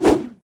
handswing4.ogg